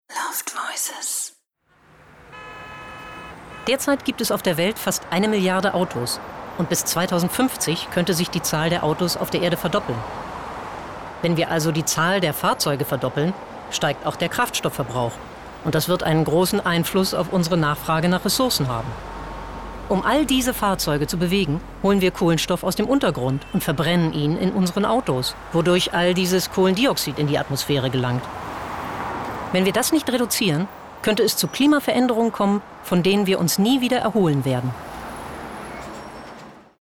markant
Norddeutsch
Doku